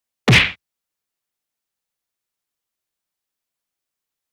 赤手空拳击中肉体4-YS070524.wav
通用动作/01人物/03武术动作类/空拳打斗/赤手空拳击中肉体4-YS070524.wav
• 声道 立體聲 (2ch)